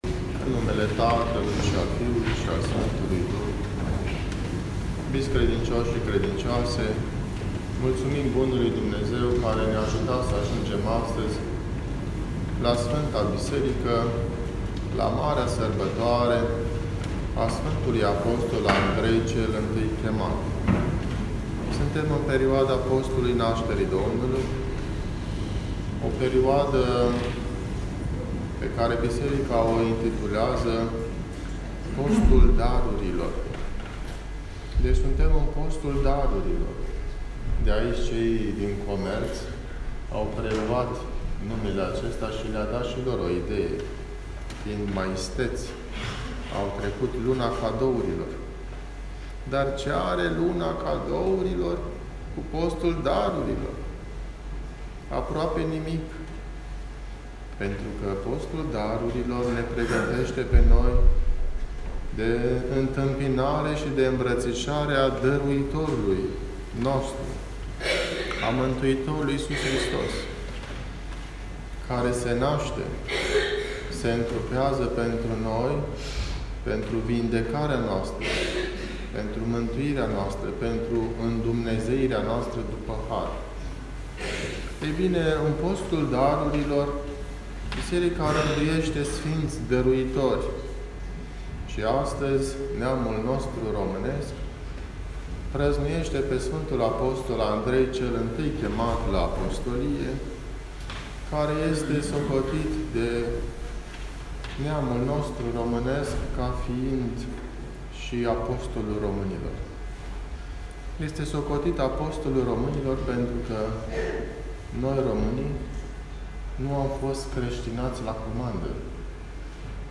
Predica de la sărbătoarea Sf. Andrei – Audio